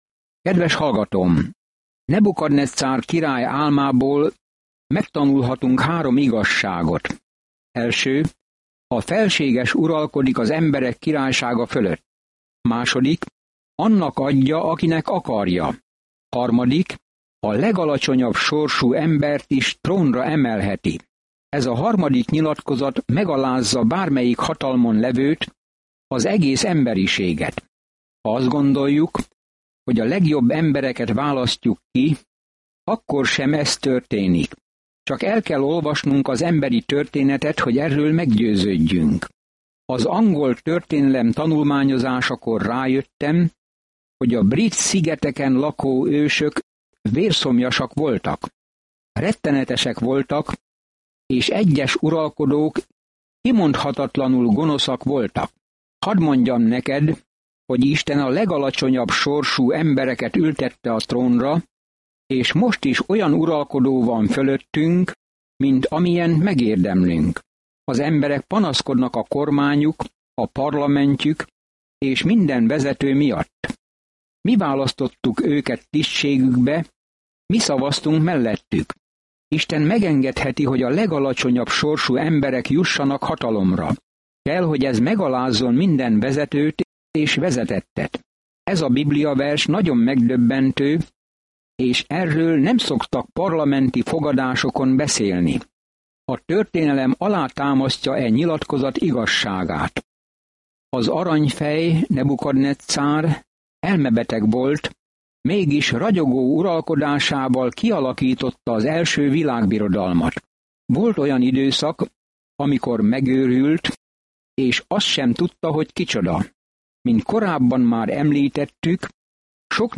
Szentírás Dániel 4:15-34 Nap 9 Olvasóterv elkezdése Nap 11 A tervről Dániel könyve egyszerre egy olyan ember életrajza, aki hitt Istenben, és egy prófétai látomás arról, hogy ki fogja végül uralni a világot. Napi utazás Dánielen keresztül, miközben hallgatod a hangos tanulmányt, és olvasol válogatott verseket Isten szavából.